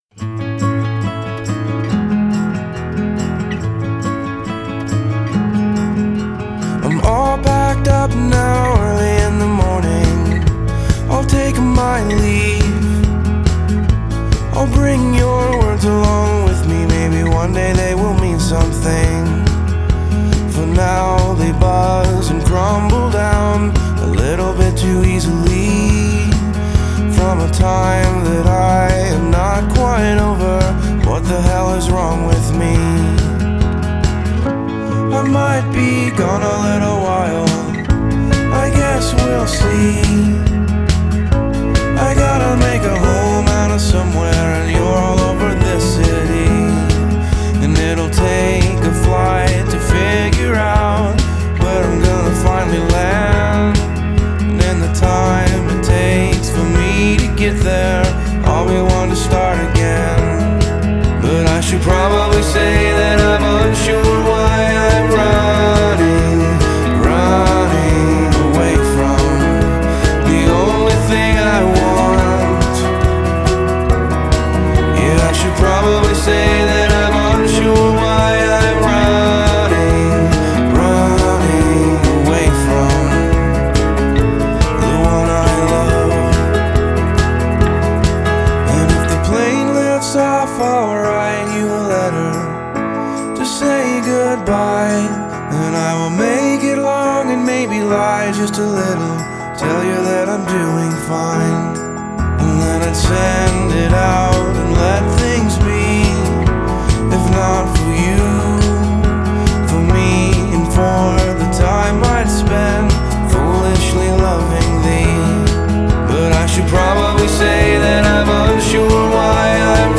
are hooky with just a hint of darkness